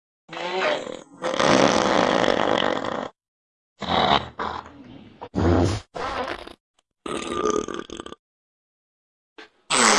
Еще немного качественного пердежа
toilet2.wav